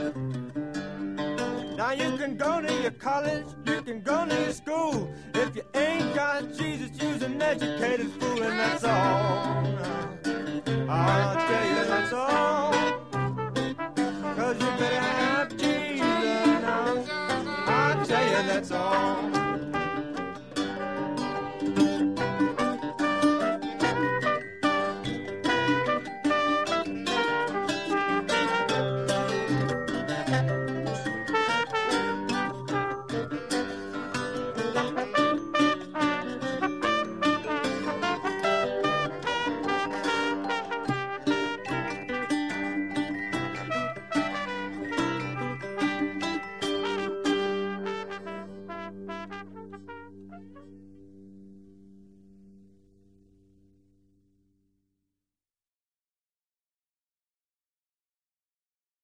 obscure gospel song